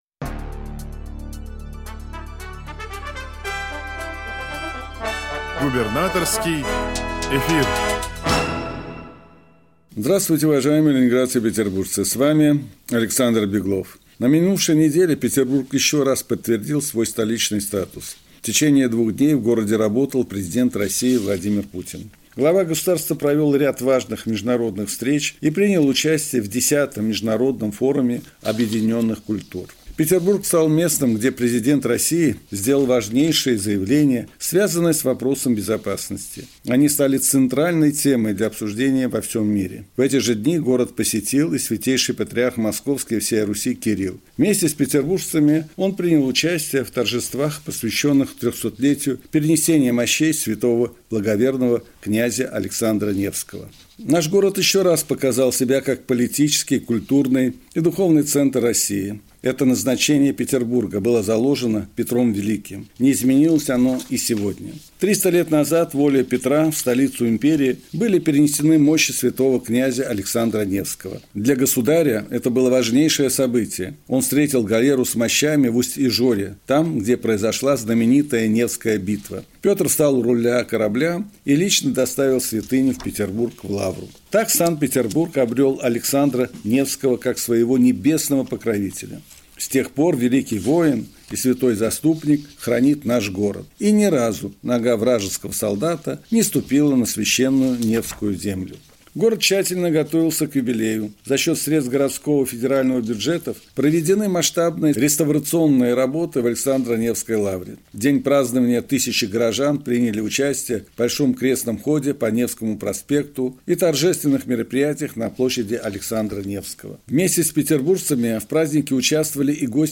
Радиообращение – 16 сентября 2024 года